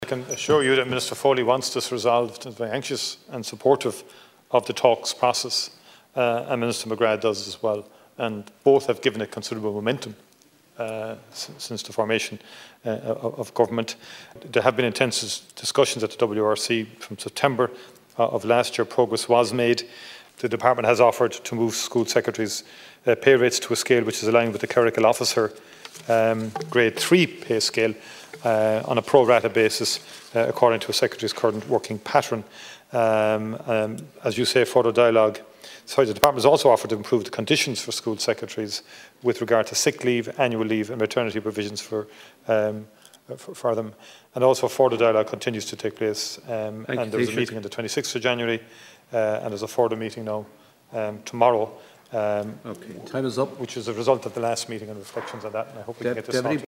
In response, the Taoiseach says there is a will to find a resolution: